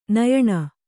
♪ nayaṇa